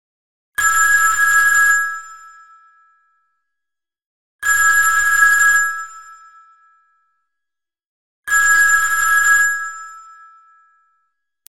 Kategorien: Telefon